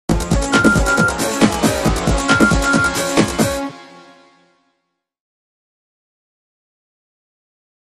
Music Logo; Short Dance Groove Beat, With An Emergency Feel.